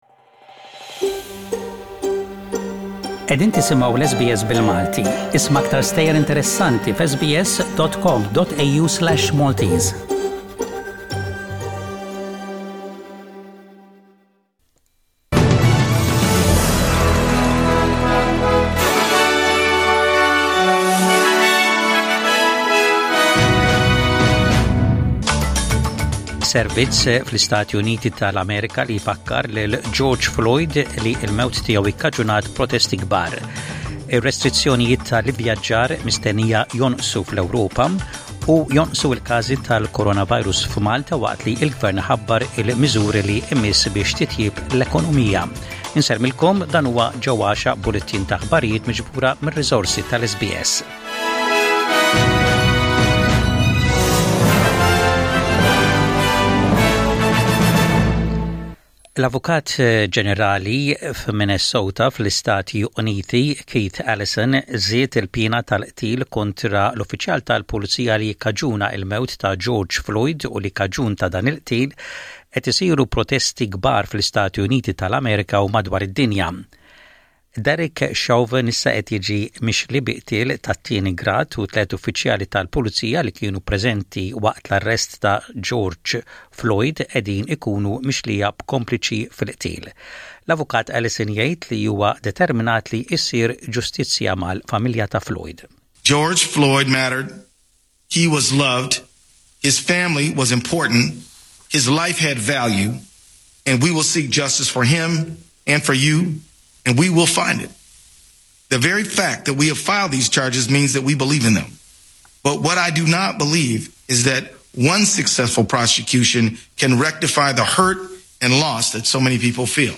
Maltese News